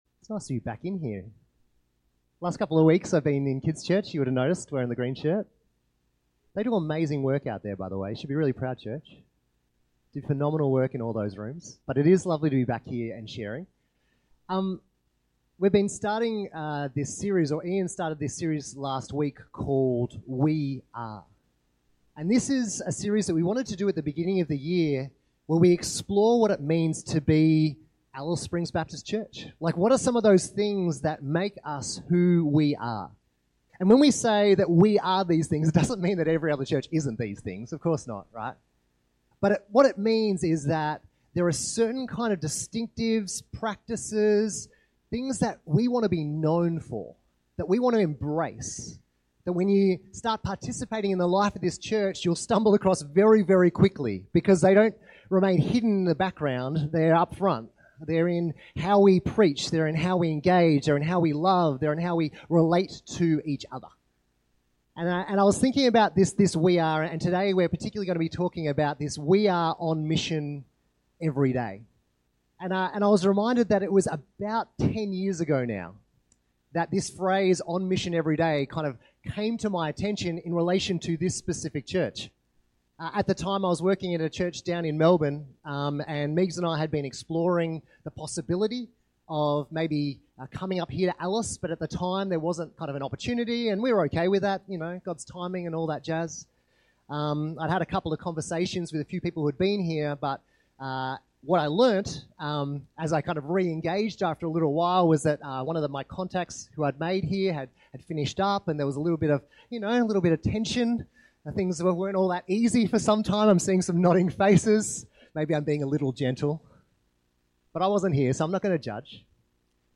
Sermons – Alice Springs Baptist Church